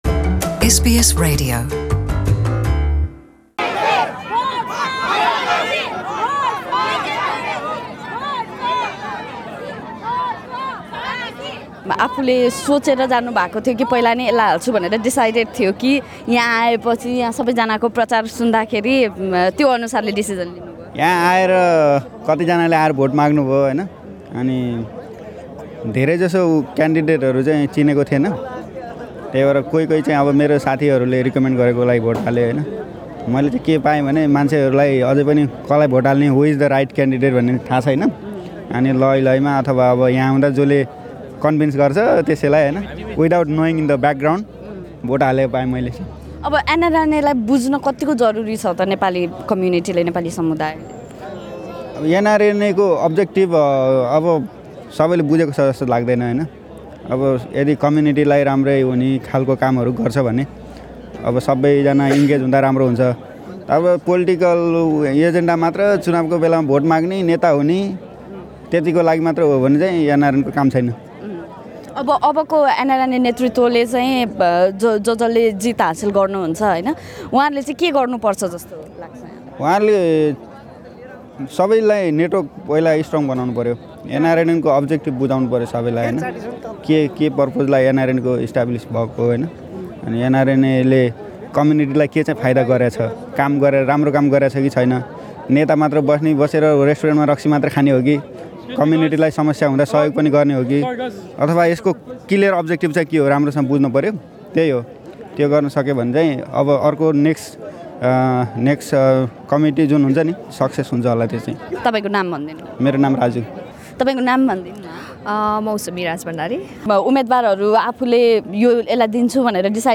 निर्वाचन स्थलमा रहेका नेपालीहरू सँग गरिएको कुराकानी सुन्न माथिको मिडिया प्लेयरमा प्ले बटन थिच्नुहोस्